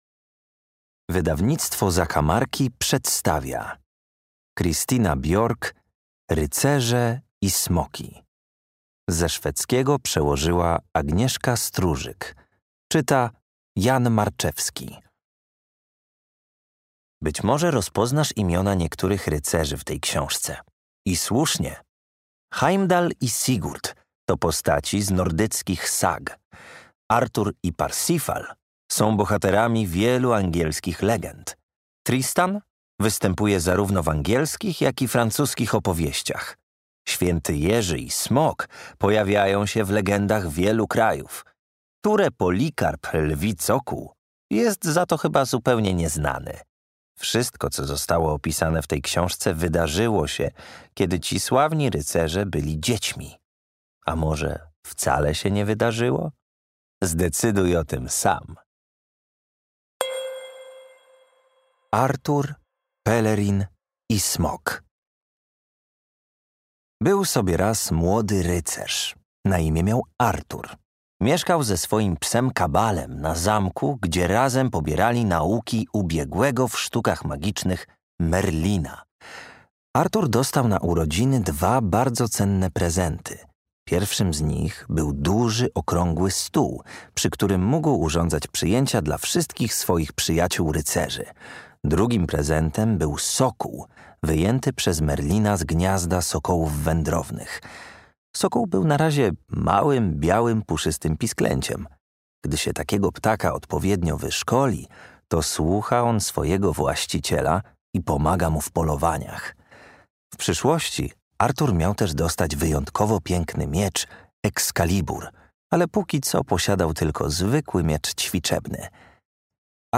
Księżniczki i rycerze. Rycerze i smoki - Christina Björk - audiobook